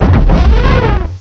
cry_carracosta.aif